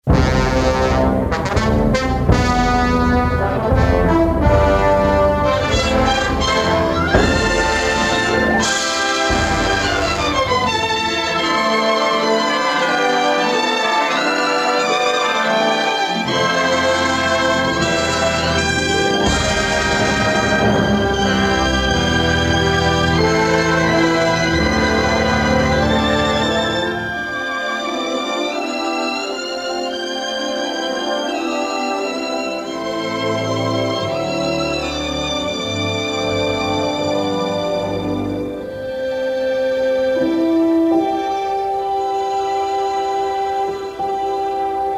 yearning and beautiful melodies of his own.